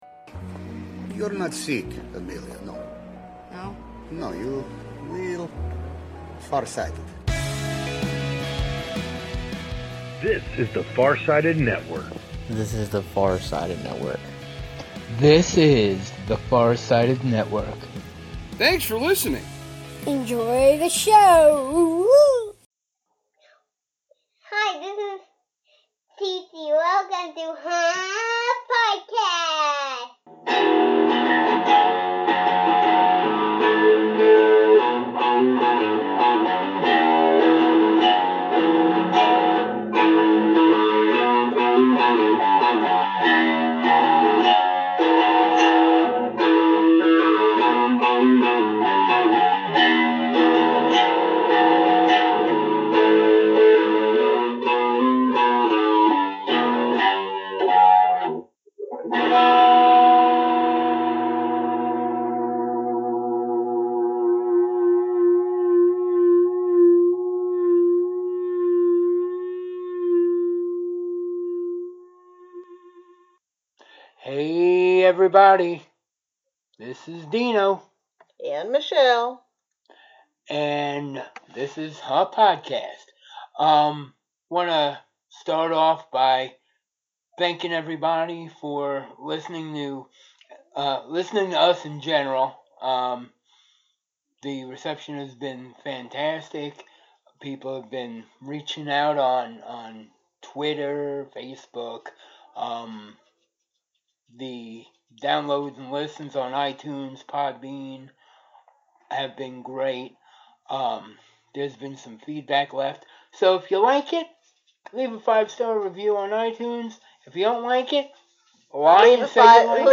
have the honor of interviewing not one but two independent horror filmmakers.